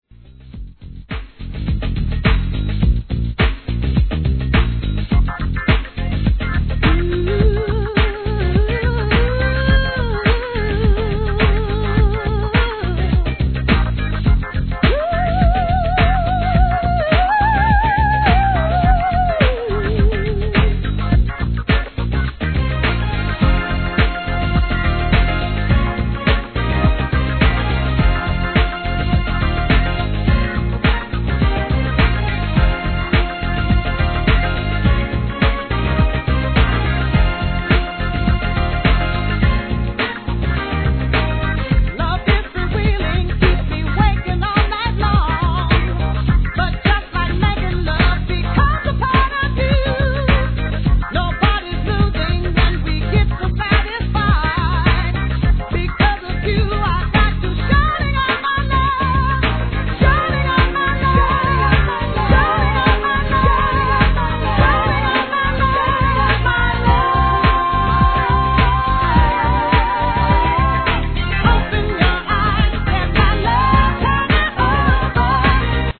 HIP HOP/R&B
テンポを落とし力強いヴォーカルが引き立った良作